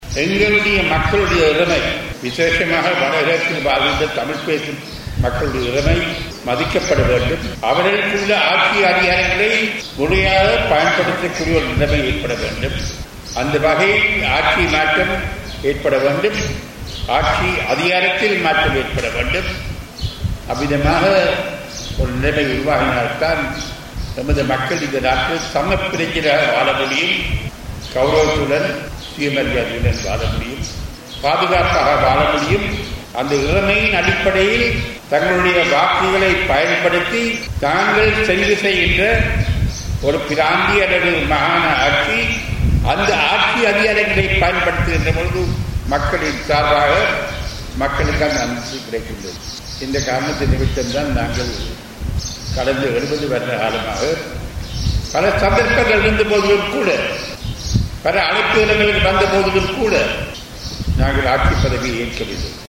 தமிழ் பேசும் மக்களின் இறைமைகள் மதிக்கப்படல் மற்றும் ஆட்சி மாற்றங்கள் ஏற்பட வேண்டும் உள்ளிட்ட விடயங்களை கருத்திற்கொண்டே, கடந்த 70 வருடங்களாக தாம் சார்ந்த கட்சிகள் ஆட்சி பொறுப்பை ஏற்கவில்லை என எதிர்கட்சி தலைவர் இரா.சம்பந்தன் தெரிவித்துள்ளார். மன்னாரில் இடம்பெற்ற மக்கள் கலந்துரையாடலில் கலந்து கொண்ட போதே அவர் இதனை தெரிவித்துள்ளார்.